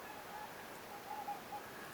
lintutornin edestä kuulunut ääni monistettuna,
siis monta kertaa peräkkäin sama ääni
Ääni kuului kolmasti siitä ruovikon reunasta,
onko tuo sirkkulaji?